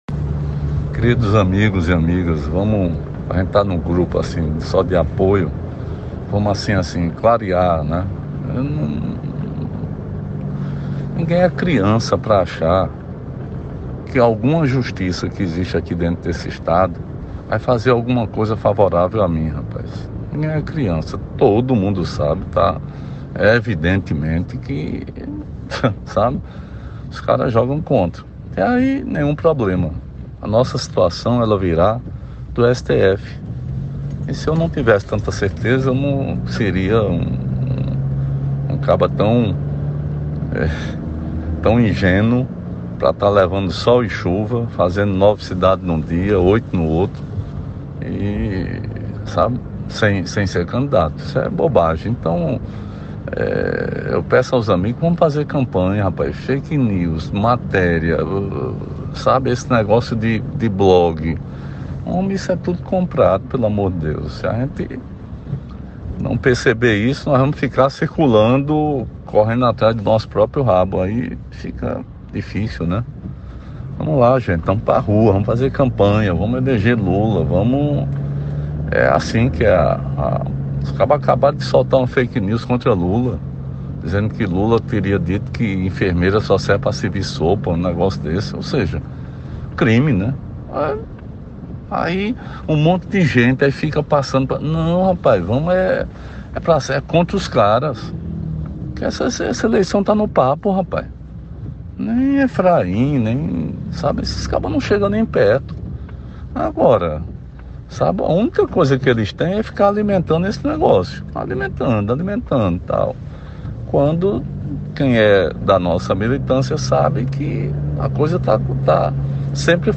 Dois dias após o Tribunal Regional Eleitoral da Paraíba (TRE-PB) seguir o que determina a lei e indeferir o registro de candidatura do inelegível Ricardo Coutinho (PT) na disputa pelo Senado Federal, um áudio gravado pelo ex-governador veio à tona.
Em pouco mais de dois minutos, Coutinho questiona a apoiadores, em tom de deboche, o trabalho do judiciário paraibano.